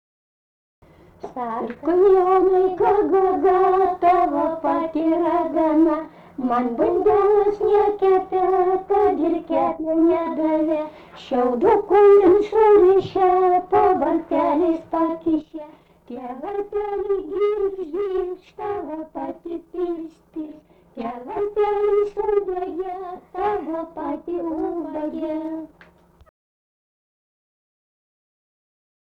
Anykščiai
vokalinis